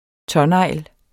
Udtale [ ˈtʌ- ]